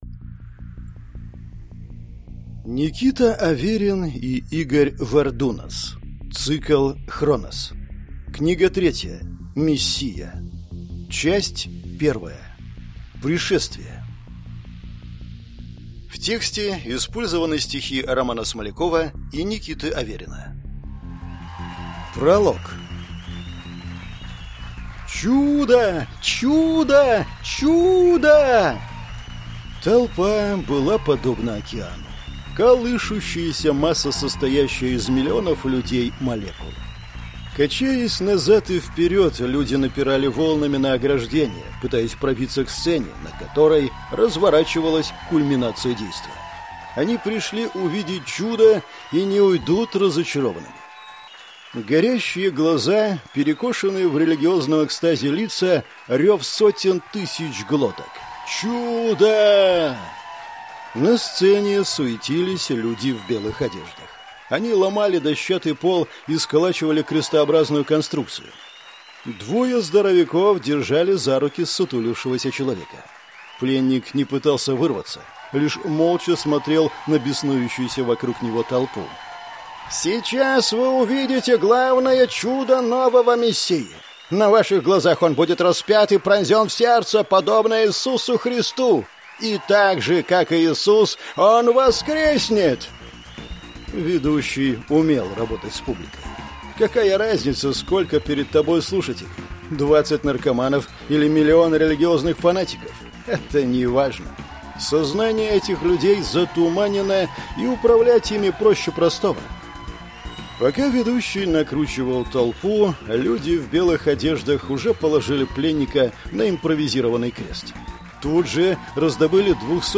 Aудиокнига
Прослушать и бесплатно скачать фрагмент аудиокниги